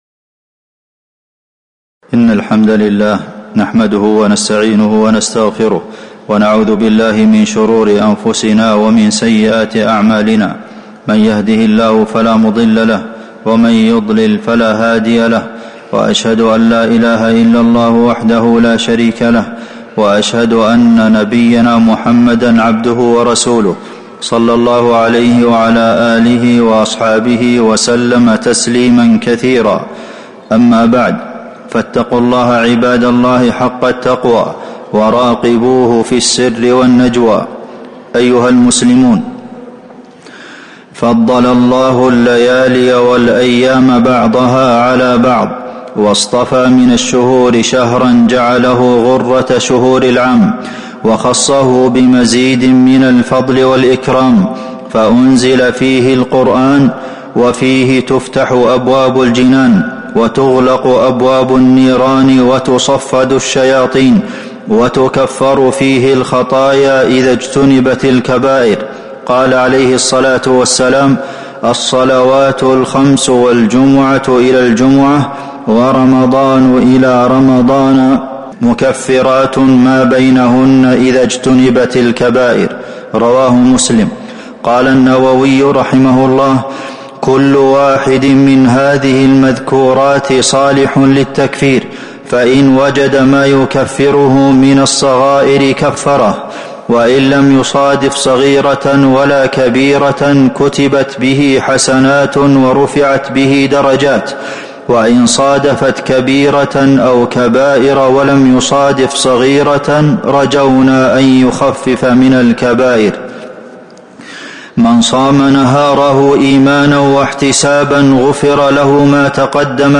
تاريخ النشر ١٢ رمضان ١٤٤٥ هـ المكان: المسجد النبوي الشيخ: فضيلة الشيخ د. عبدالمحسن بن محمد القاسم فضيلة الشيخ د. عبدالمحسن بن محمد القاسم من فضائل شهر رمضان